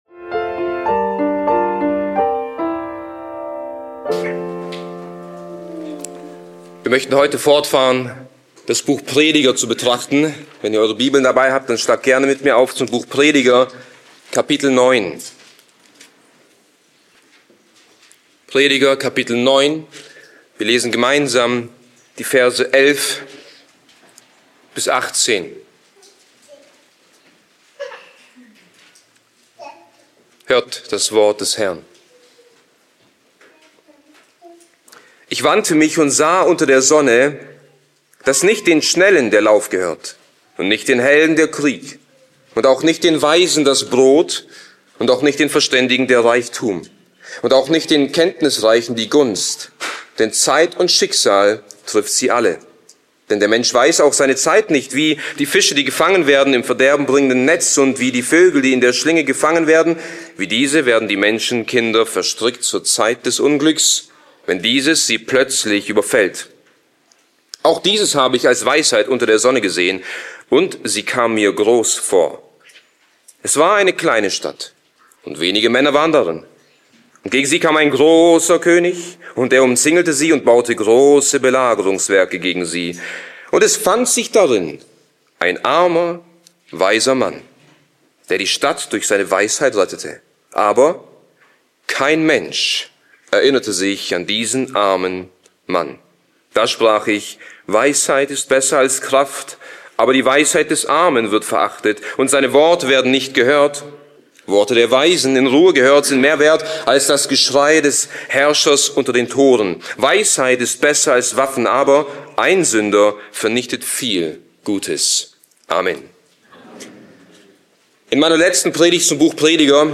Bibeltreue Predigten der Evangelisch-Baptistischen Christusgemeinde Podcast